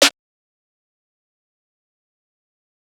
Sn (LetEmKnow)_2.wav